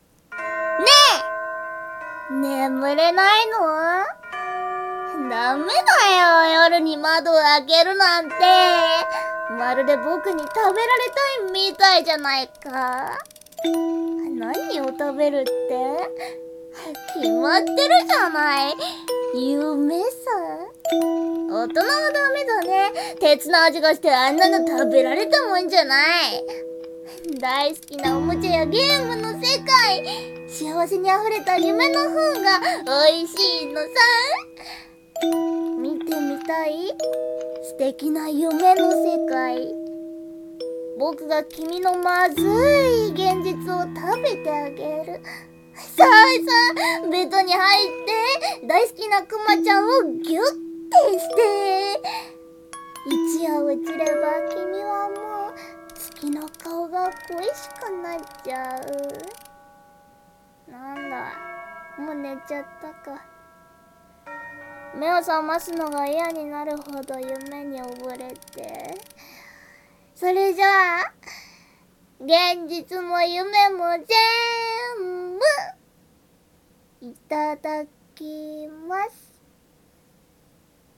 【声劇】ユメ喰いバク